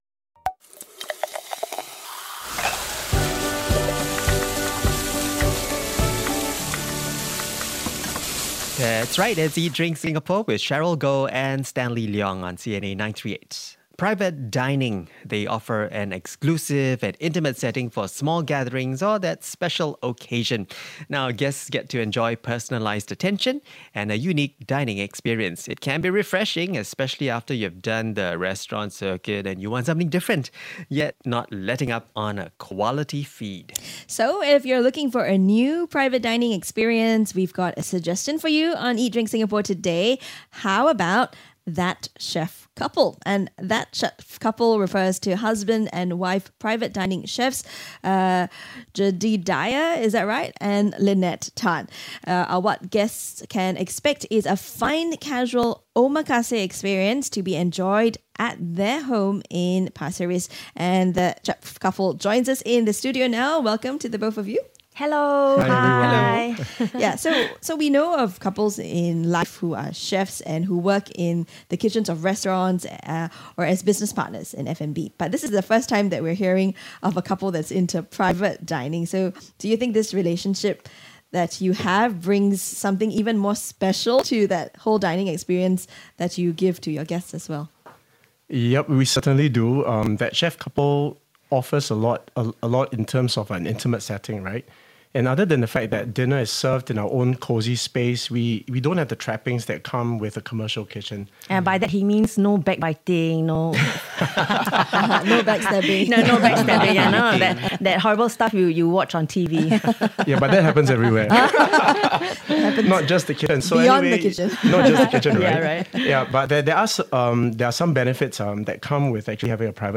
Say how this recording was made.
The live interview on CNA 938 was on 16 May 2024